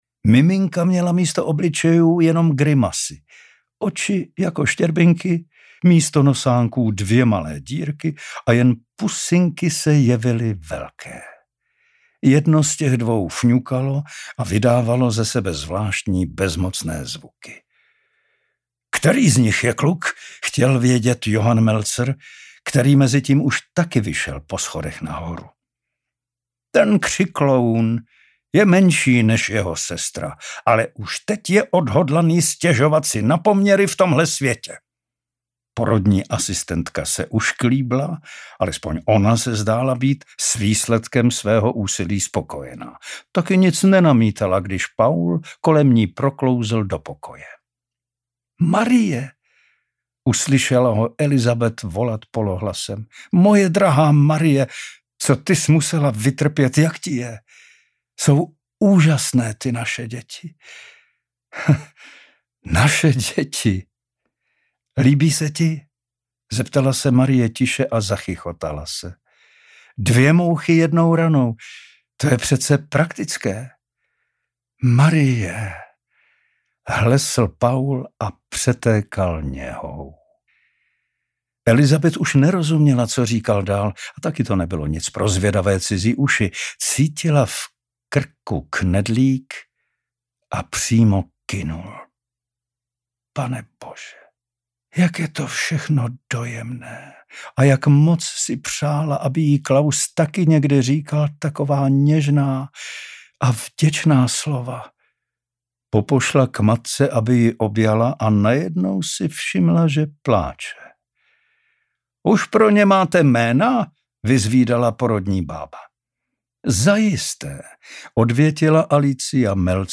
Natočeno ve studiu Chevaliere
Obálka audioknihy Panský dům